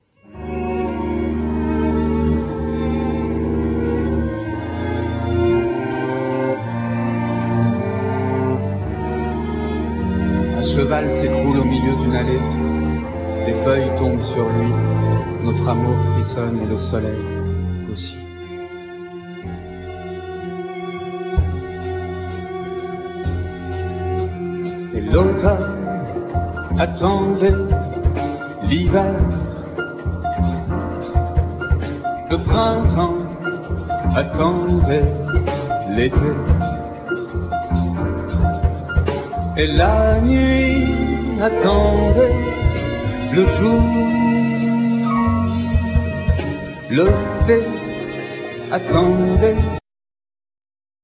Live enregistre aux Francofolies de La Rochelle 2000
Vocals
Piano
Contrabass
Drums
1st Violin
Viola
Cello